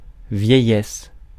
Ääntäminen
IPA : /eɪdʒ/